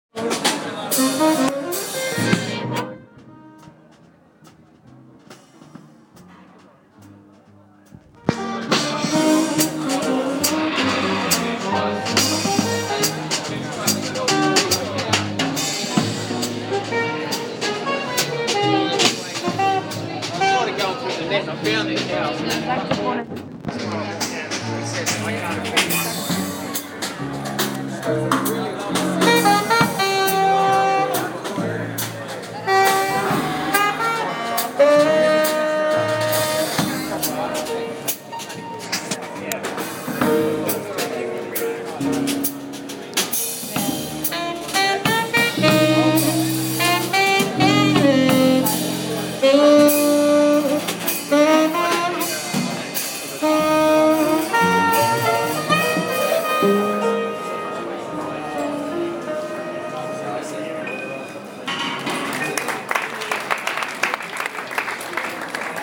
Street Jazz
Dubrovnik, Croatia